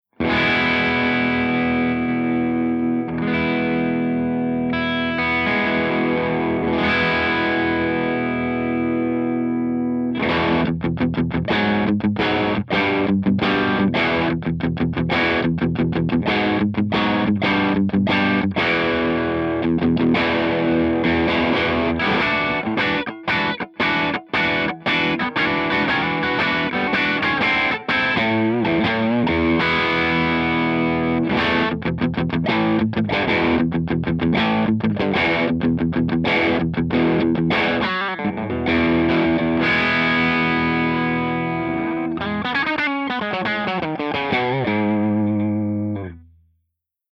082_MARSHALLJTM60_CH2CRUNCH_P90